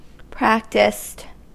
Ääntäminen
Synonyymit proficient Ääntäminen US Haettu sana löytyi näillä lähdekielillä: englanti Käännös Adjektiivit 1. praktiziert Practiced on sanan practice partisiipin perfekti.